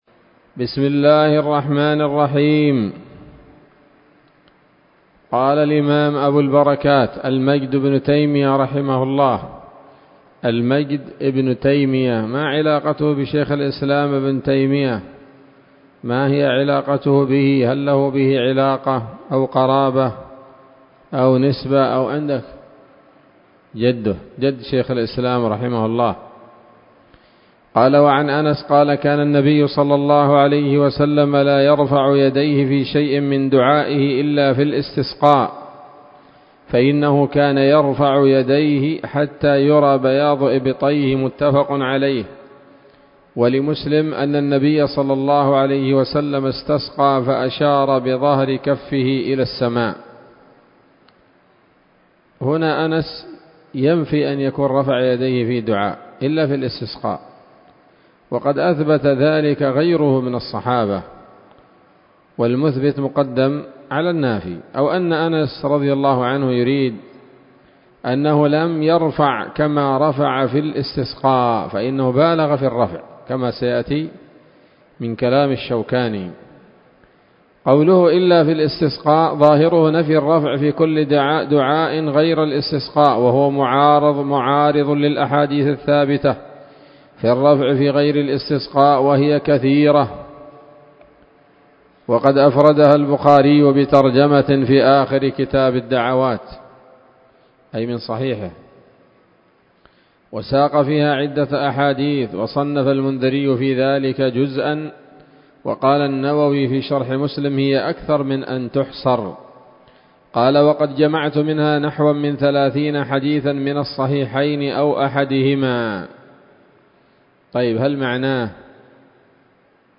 الدرس الرابع من ‌‌‌‌كتاب الاستسقاء من نيل الأوطار